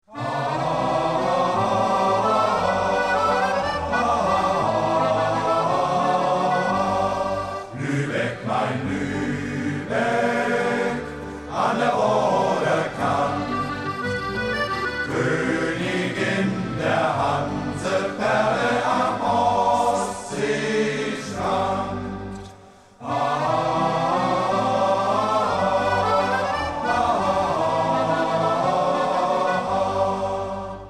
Aufgenommen im Tonstudio